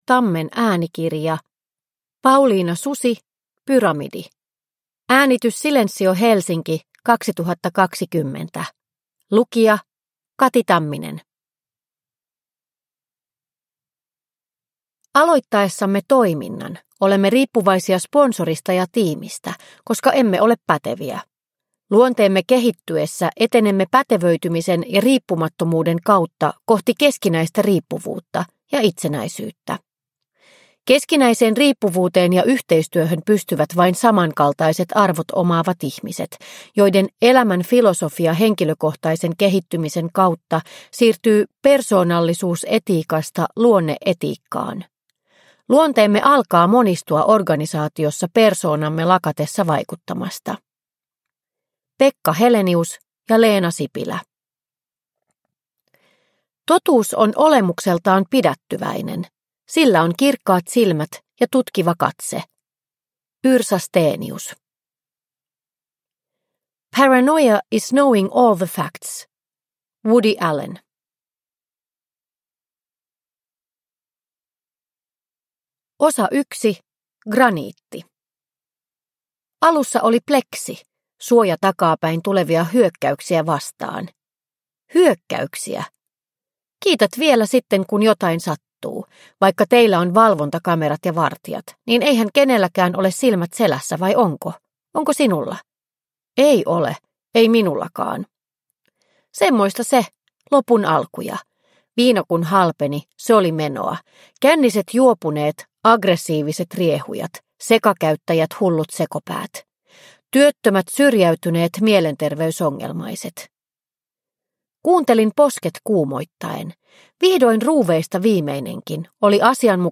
Pyramidi – Ljudbok – Laddas ner